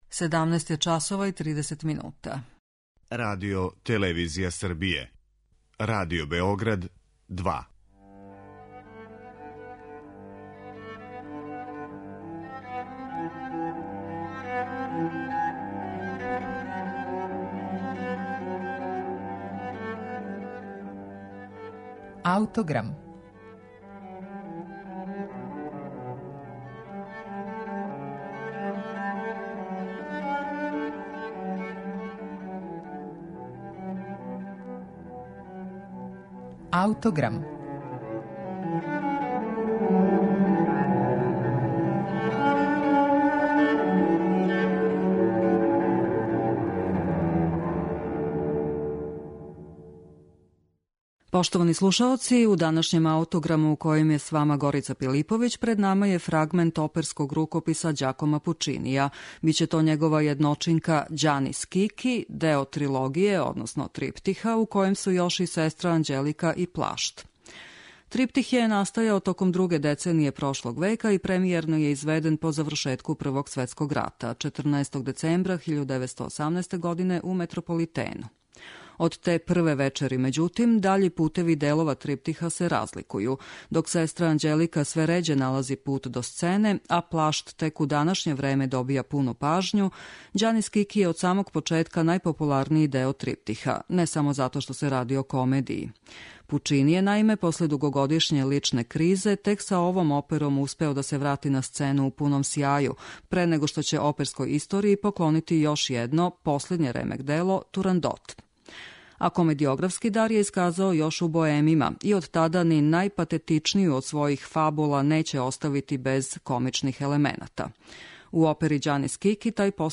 Ова опера ће испунити данашњи Аутограм